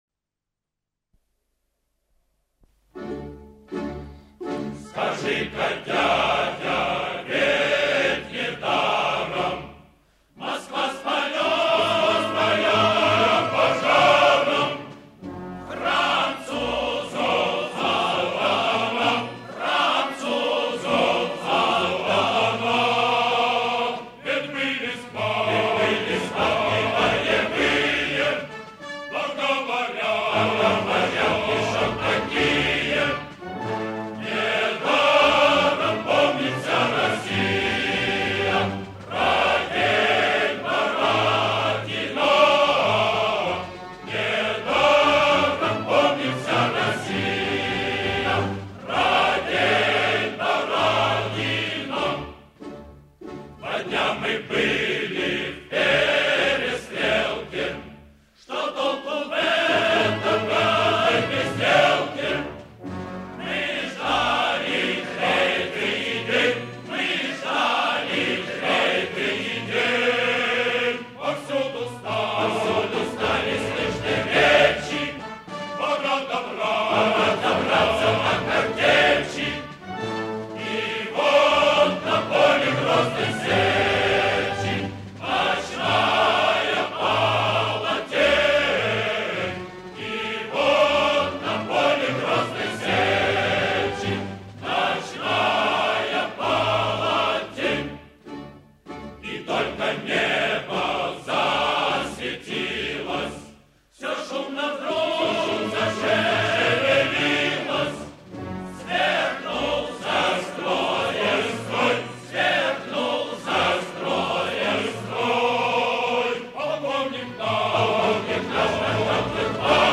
файл) 2,99 Мб Русская народная песня «Бородино» на слова М.Ю. Лермонтова. 1